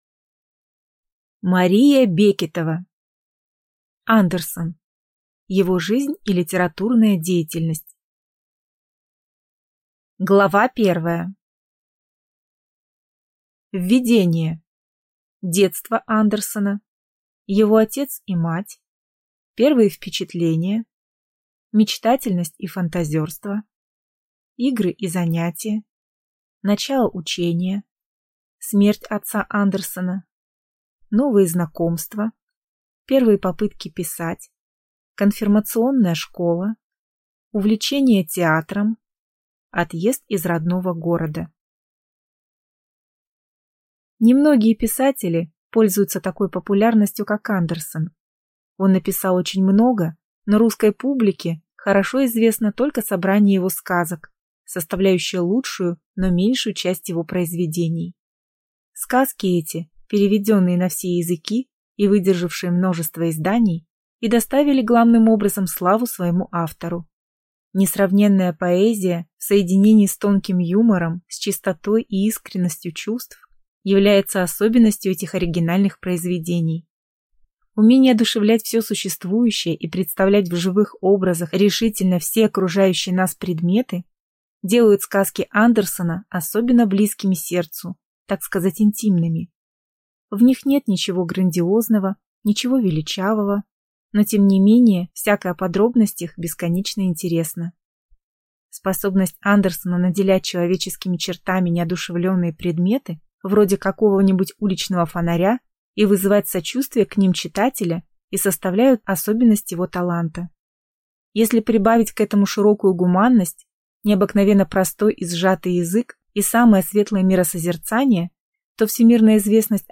Аудиокнига Андерсен. Его жизнь и литературная деятельность | Библиотека аудиокниг